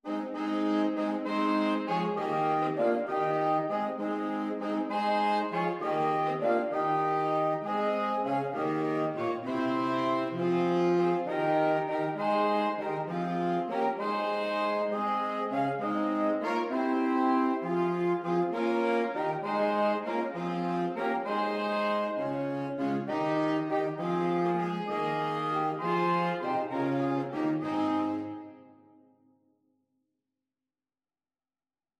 3/4 (View more 3/4 Music)
On in a bar . = c. 66
Saxophone Quartet  (View more Easy Saxophone Quartet Music)